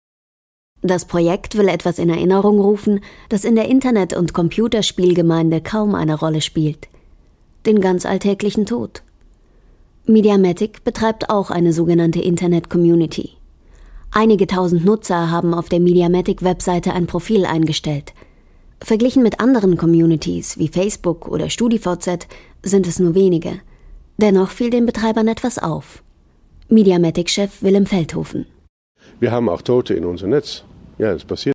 Sprecherin Werbung Stimme Welt Kompakt sachlich seriös sinnlich frech lieblich kindlich derb erotisch Hörbuch Dokumentation Reportage Synchron ausgebildet
Sprechprobe: Sonstiges (Muttersprache):
professional narrator and voice-over artist with a unique voice and expression